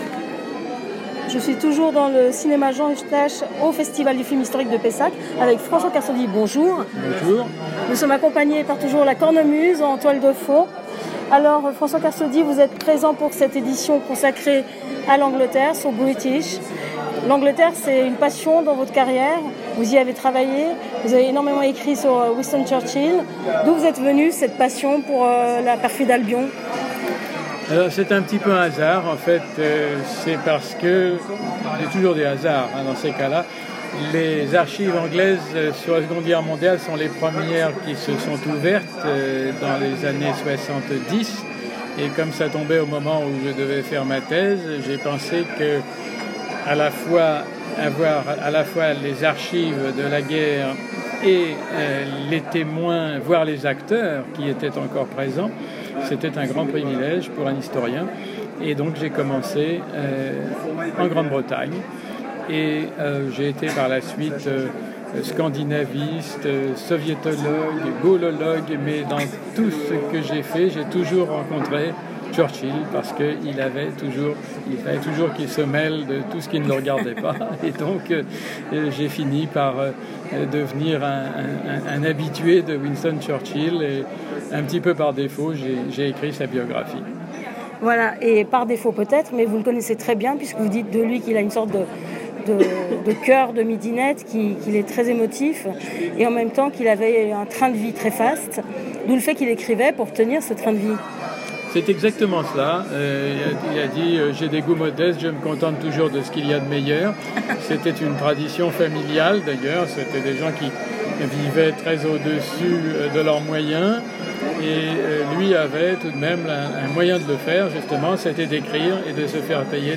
Rencontré le premier jour du Festival de Pessac, François Kersaudy nous a éclairé sur les grands stratèges du XXème siècle, sur les films qui lui sont chers, sur l’avenir de l’Europe post Brexit, en avouant avec modestie se tenir informé dans a presse des neuf langues qu’il parle.
Rencontre.